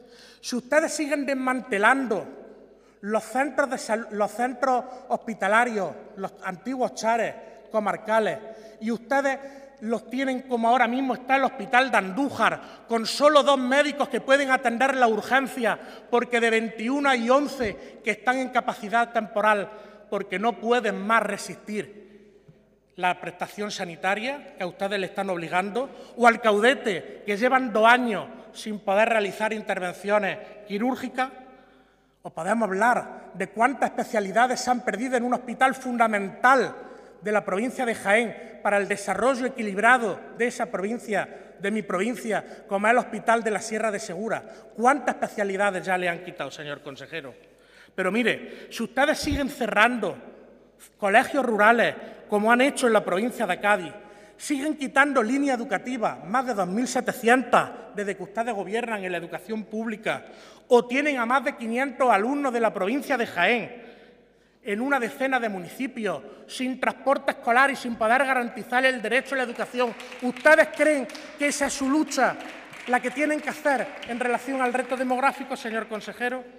En el Pleno del Parlamento
Cortes de sonido # Víctor Torres